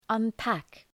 Προφορά
{ʌn’pæk}